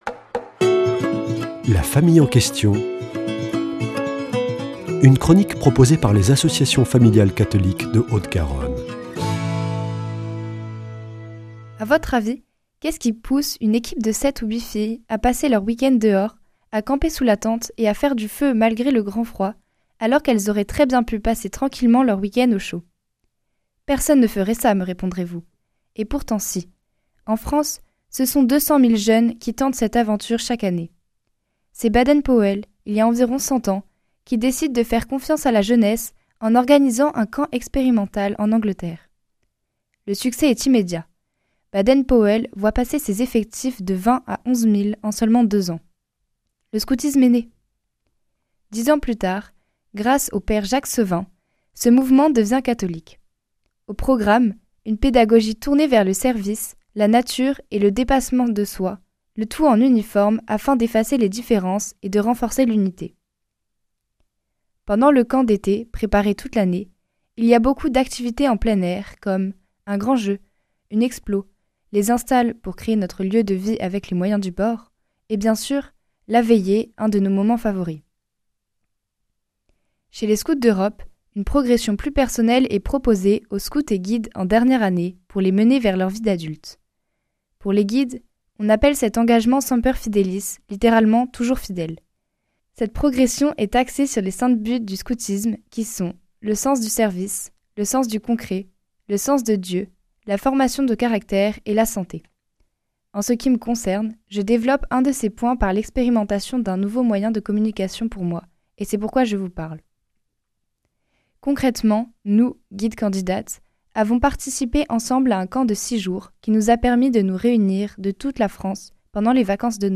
Une guide vous parle du scoutisme